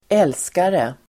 Uttal: [²'el:skare]